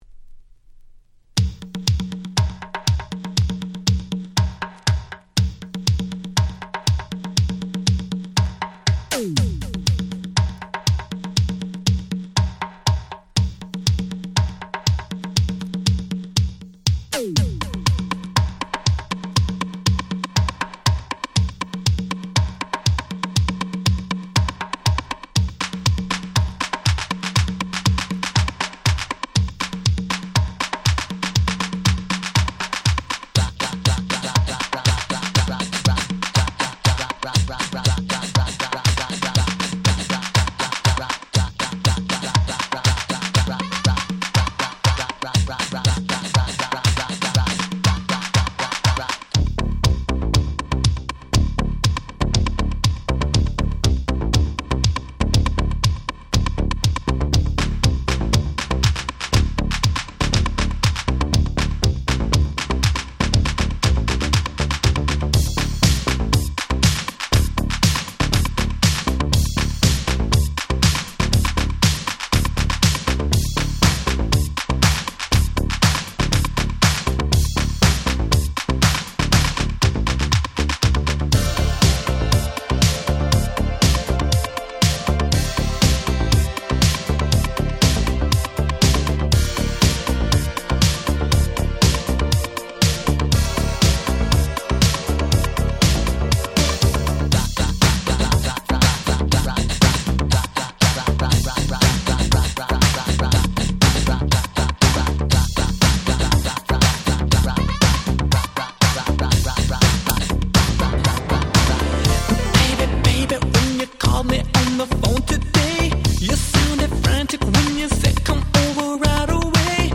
88' Super Hit R&B / Disco !!
今聴いても普通に格好良いディスコチューン！！
80's ダンクラ Dance Classics